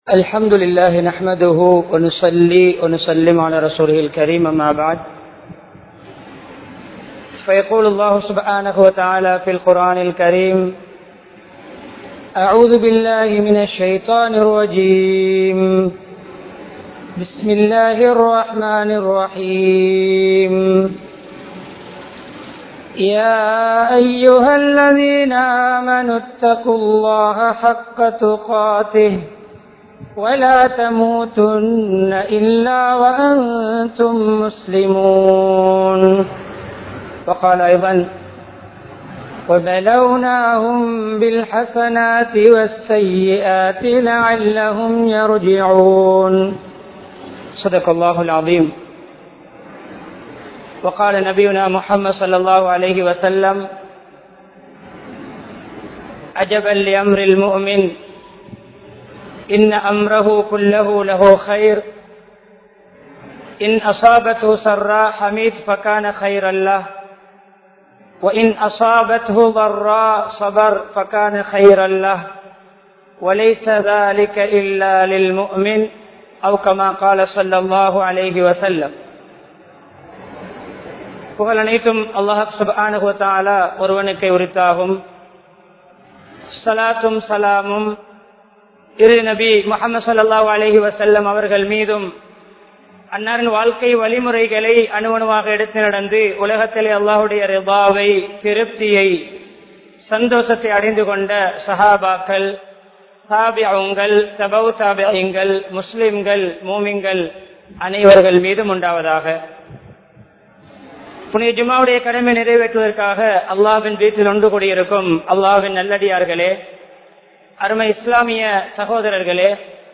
சவால்களை எவ்வாறு எதிர்கொள்வது? (How to Face the Challenges?) | Audio Bayans | All Ceylon Muslim Youth Community | Addalaichenai
Panandura, Jisthiyyah Jumuah Masjith